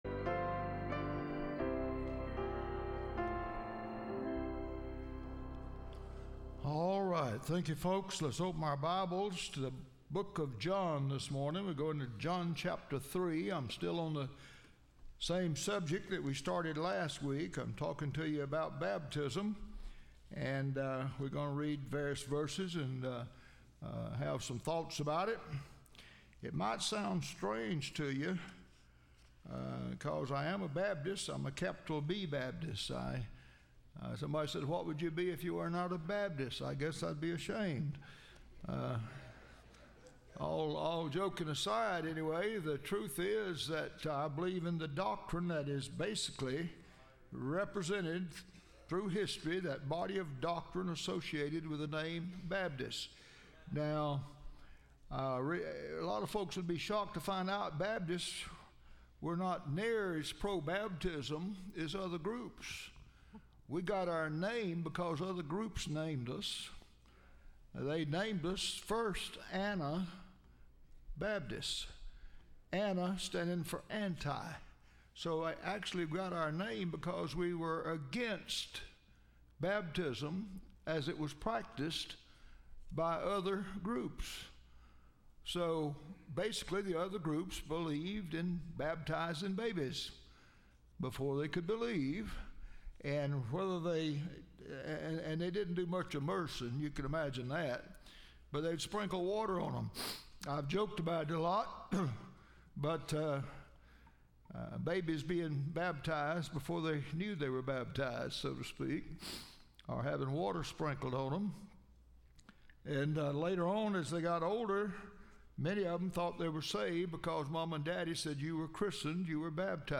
Series: Study of Baptism Service Type: Sunday School